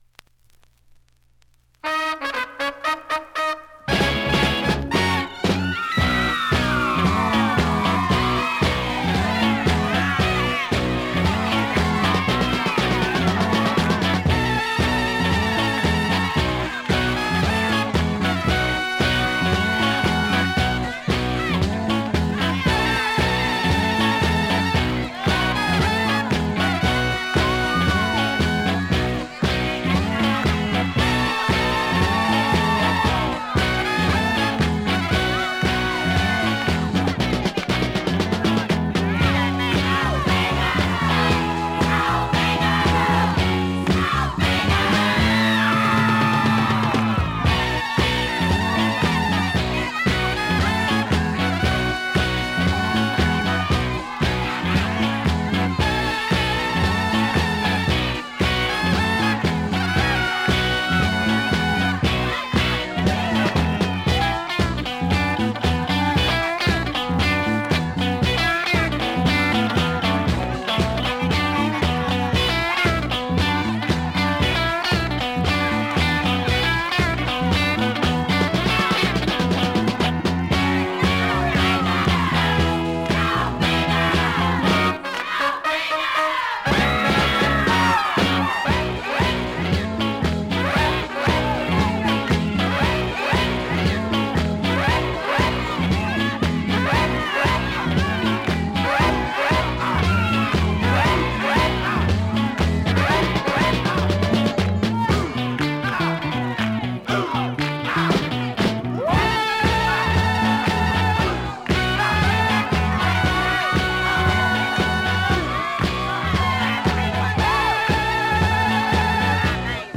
◆盤質両面/ほぼEX